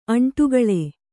♪ aṇṭugaḷe